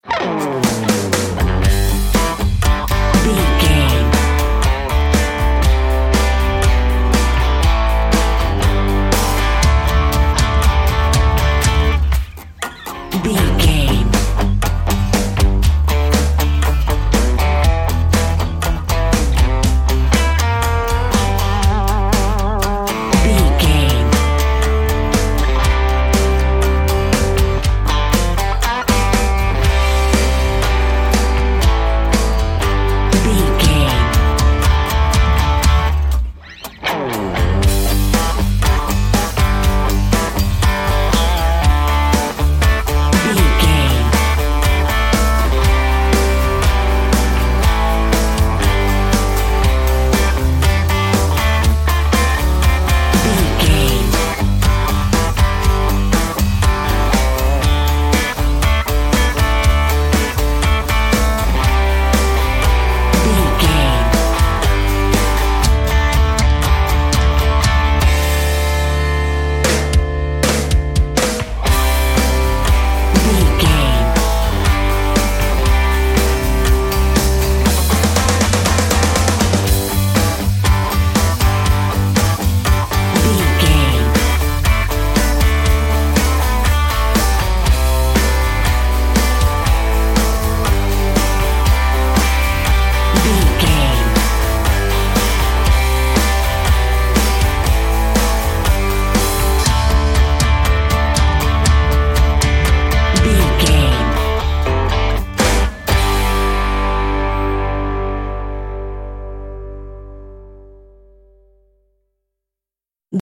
Ionian/Major
electric guitar
drums
bass guitar